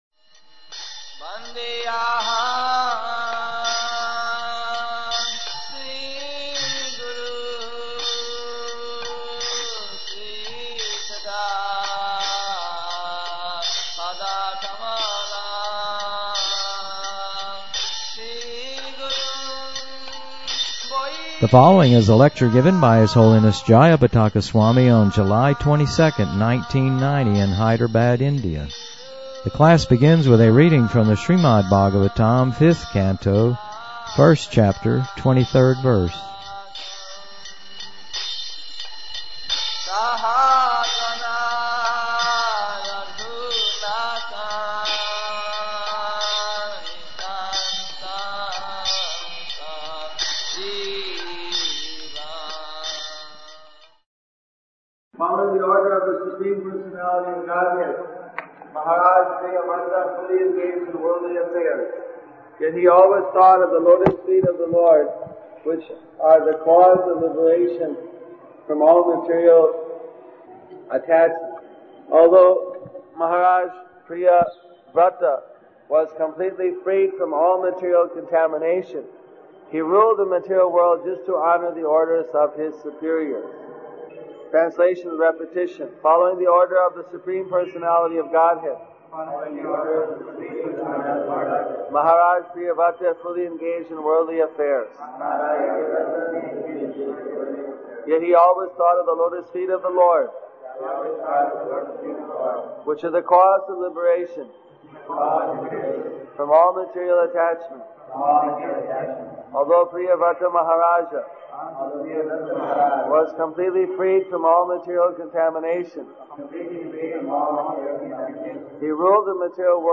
The class begins with a reading from the Srimad Bhagavatam 5th Canto 1st Chapter 23rd verse(SB5.1.23)